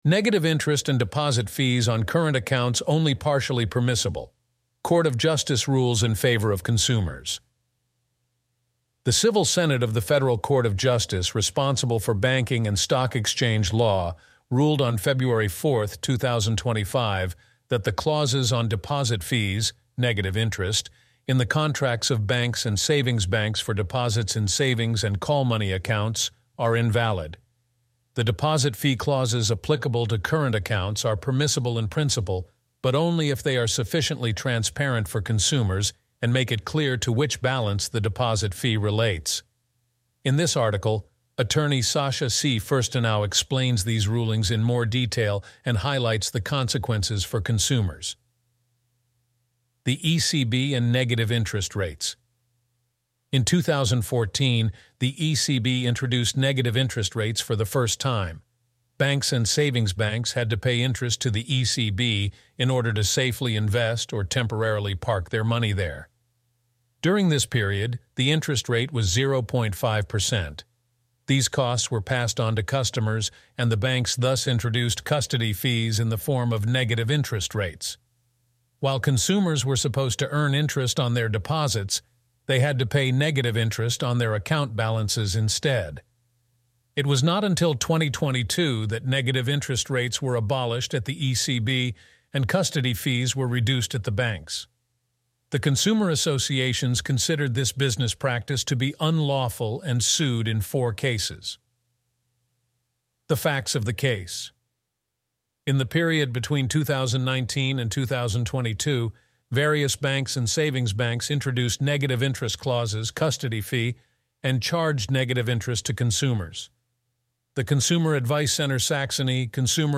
Read out the article